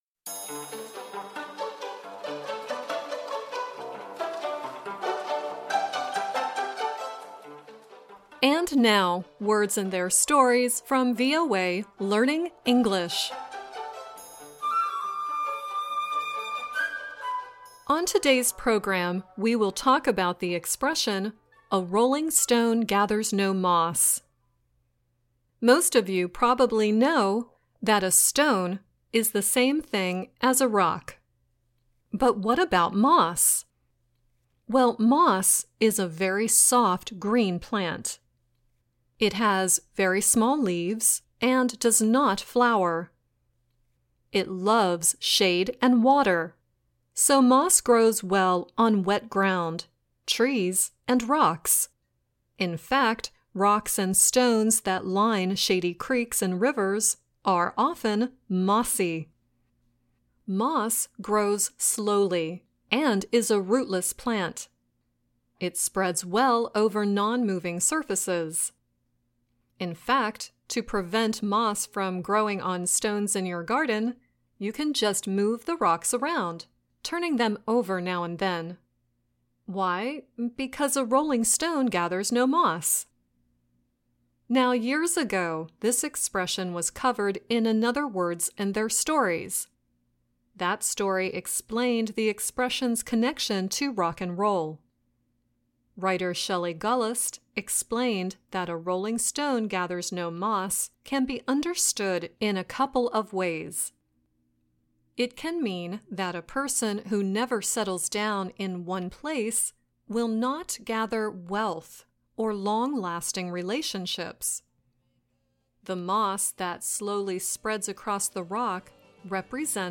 The first song used in this show is Bob Dylan singing "Rolling Stone" and the second is The Temptations singing "Papa Was a Rolling Stone."